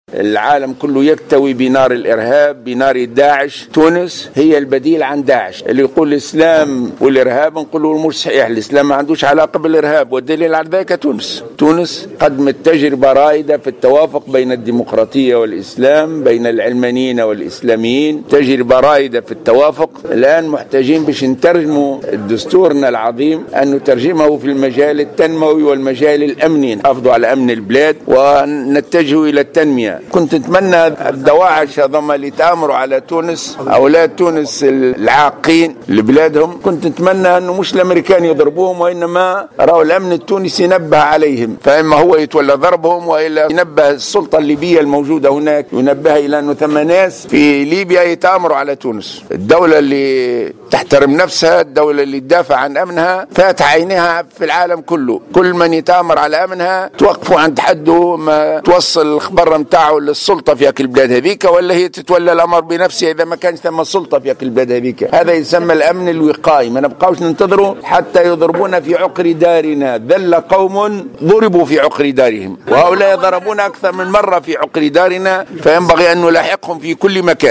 قال زعيم حركة النهضة راشد الغنوشي في تصريح للجوهرة أف أم اليوم الأحد 28 فيفري 2016 خلال حضوره في ندوة صحفية عقدت على هامش المؤتمر الجهوي لحركة النهضة في ولاية صفاقس إن تونس هي البديل عن تنظيم" داعش" الإرهابي.